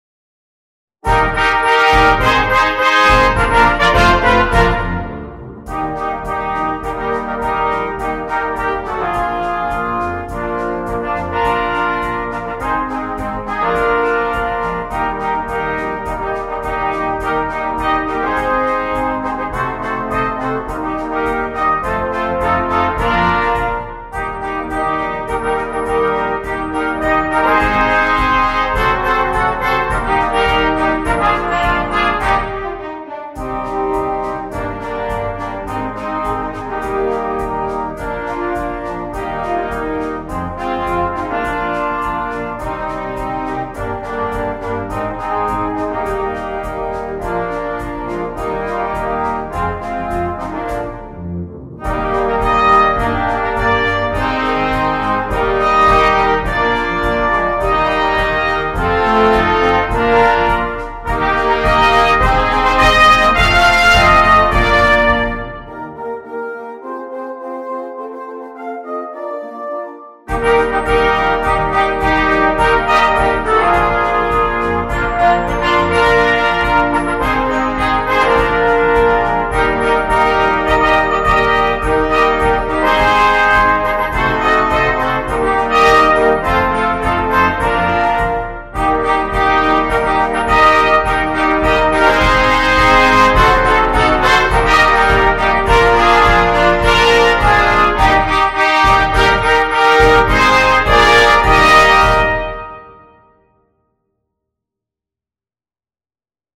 2. Brass Band
sans instrument solo
Musique de Noël